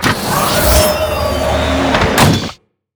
combatdronerecall.wav